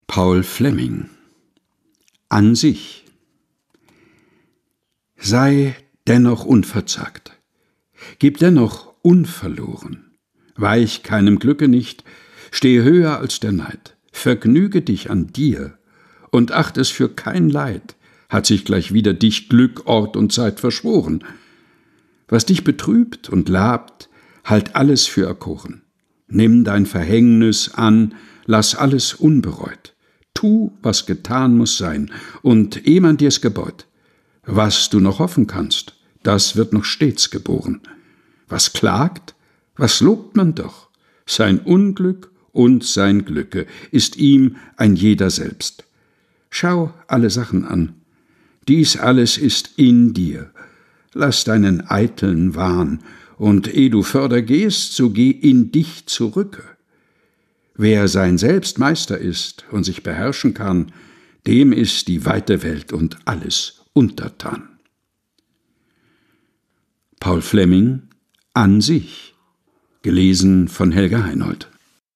Ohrenweide ist der tägliche Podcast mit Geschichten, Gebeten und Gedichten zum Mutmachen und Nachdenken - ausgesucht und im Dachkammerstudio vorgelesen